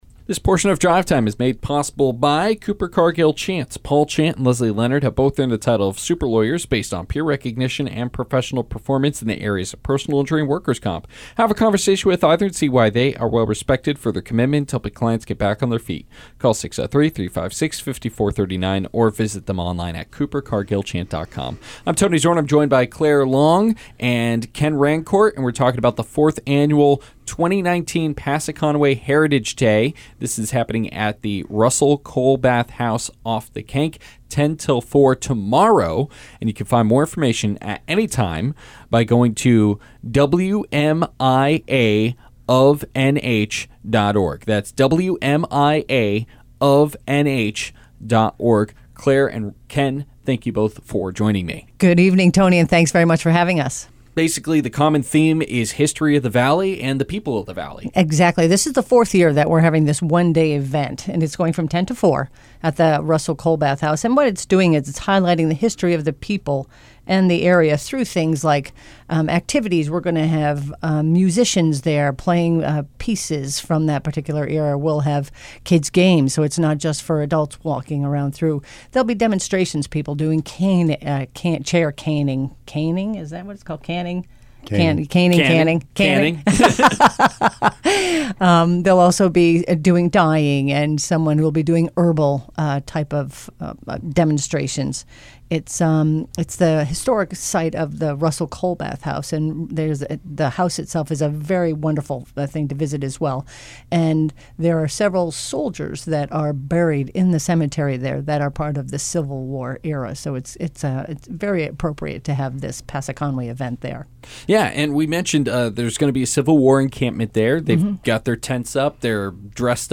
Drive Time Interviews are a specialty program on week days at 5pm where local not for profit organizations get a chance to talk about an upcoming event on air.